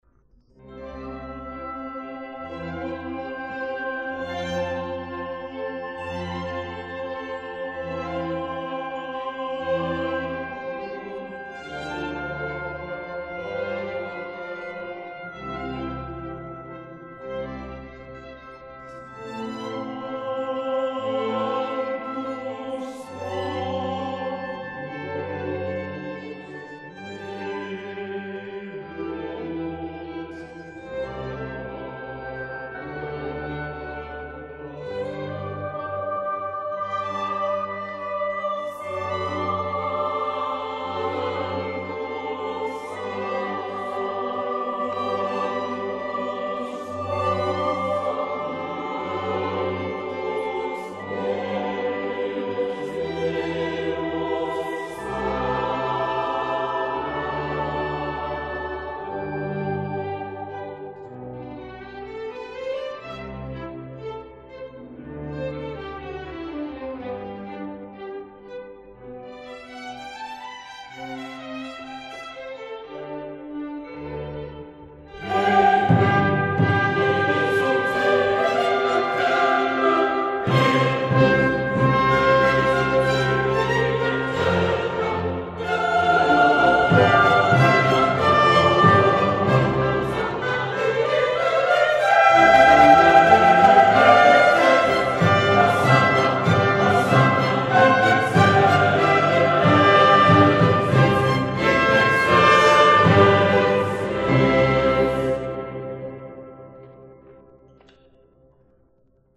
Chor und Orchester von St. Peter